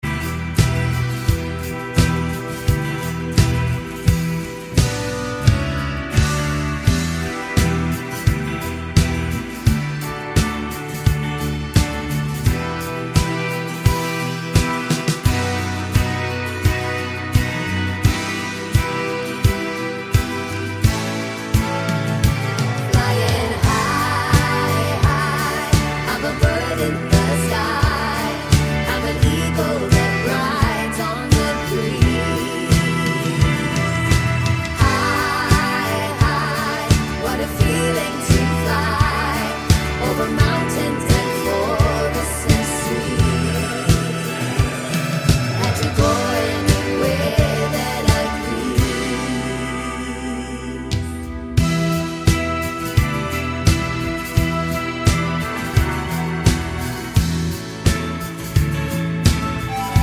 for female duet Pop (1970s) 3:42 Buy £1.50